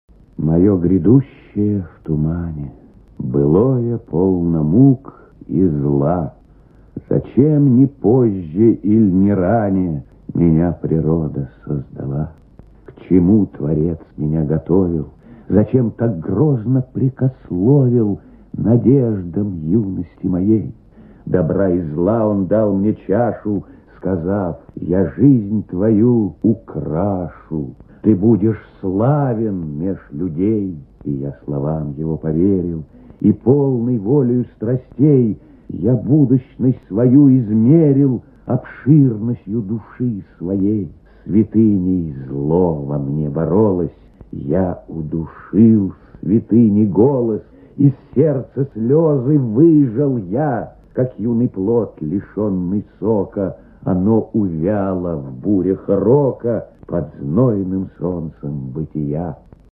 Прослушивание аудиозаписи стихотворения с сайта «Старое радио». Исполнитель О. Ефремов.